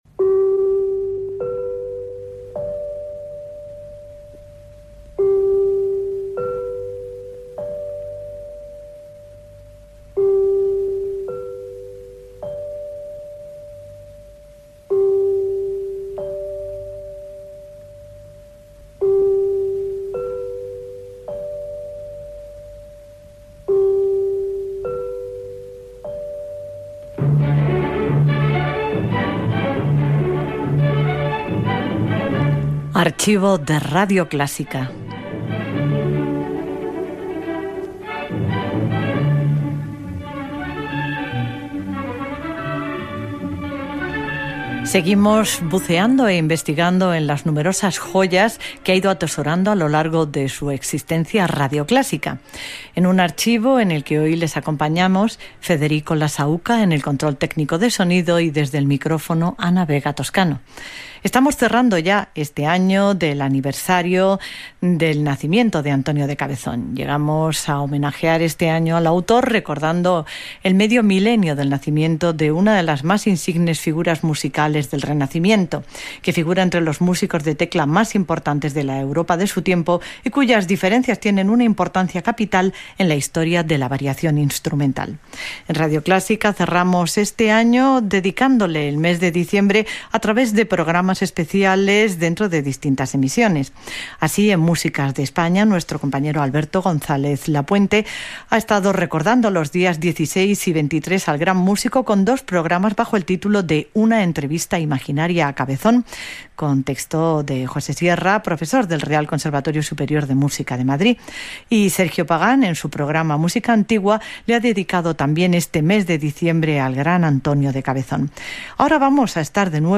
Careta del programa, espai deedicat al compositor Antonio de Cabezón i a un espai dramàtic sobre ell que Radio 2 va emetre l'any 1999 Gènere radiofònic Musical